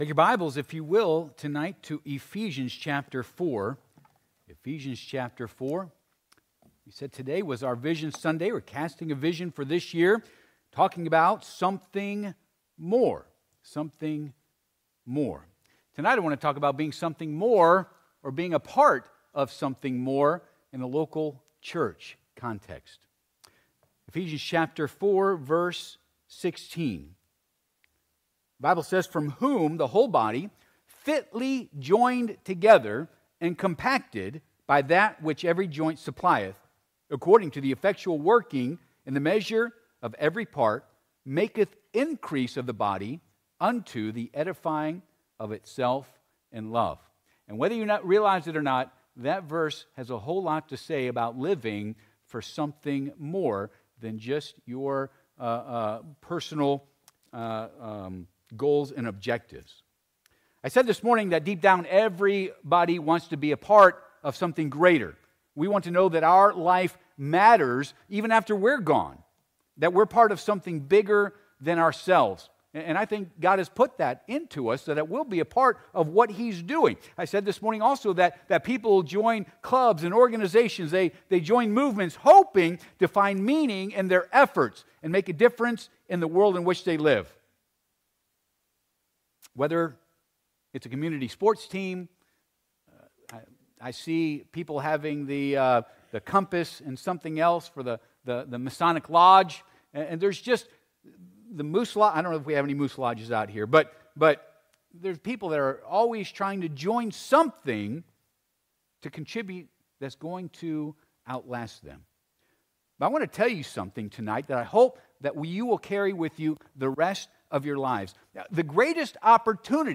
Passage: Eph. 4:16 Service Type: Sunday PM « Vision Sunday